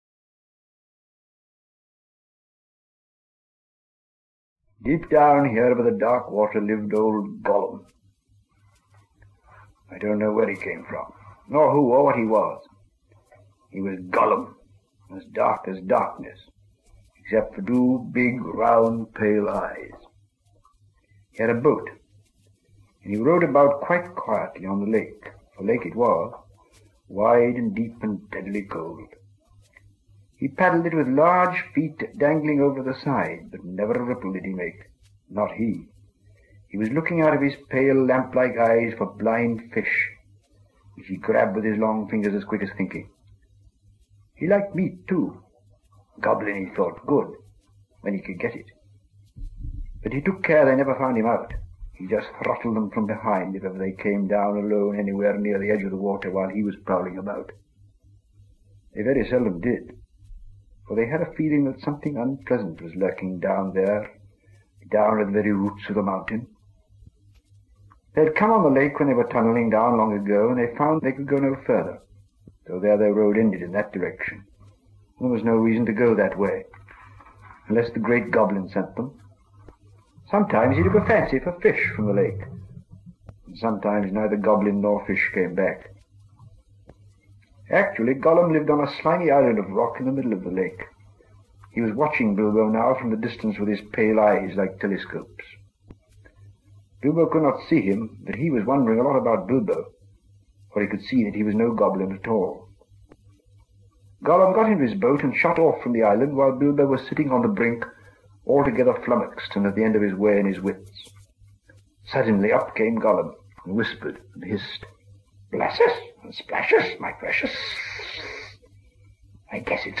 Tolkien Reads from The Hobbit in Rare Archival Audio from His First Encounter with a Tape Recorder | Brain Pickings
tolkien-reads-from-the-hobbit.mp3